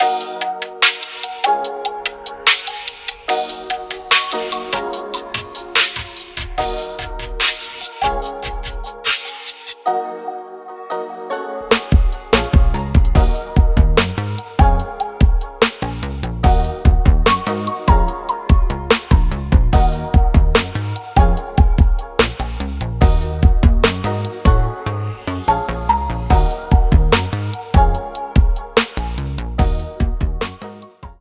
Electronic/Urban/Ambient: